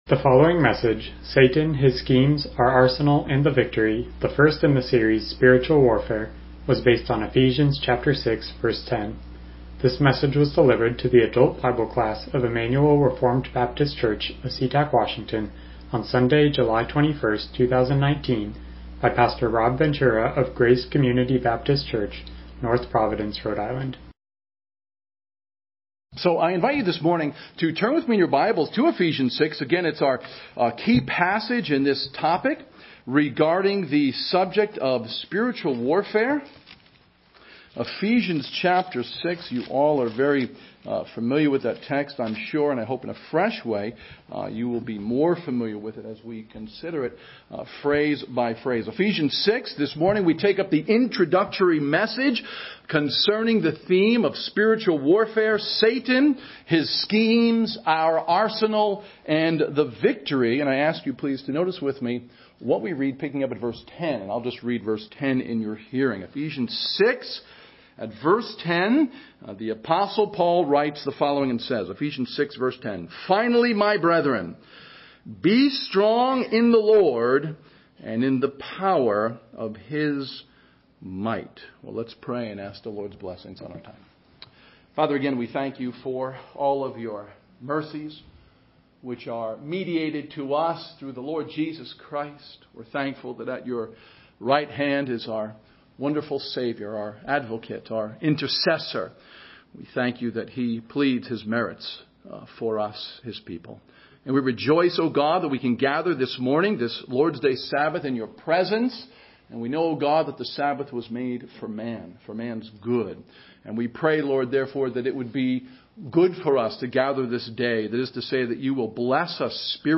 Passage: Ephesians 6:10 Service Type: Sunday School